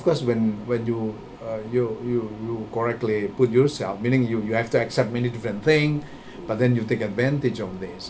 S1 = Taiwanese female S2 = Indonesian male Context: They are talking about adapting to life in Brunei.
The unexepected stress on the first syllable of yourself seems to cause this misunderstanding, as one might expect the intonational nucleus to be on put rather than the following reflexive pronoun yourself . Note that proDUCer (stressed on the second syllable) matches the stress pattern of put YOURself .